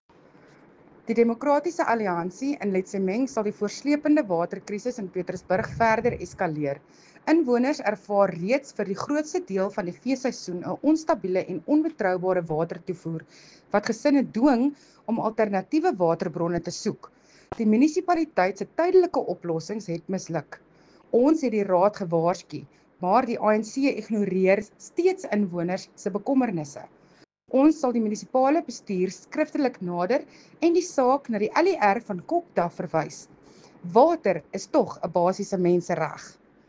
Afrikaans soundbite by Cllr Mariska Potgieter and Sesotho soundbite by Jafta Mokoena MPL.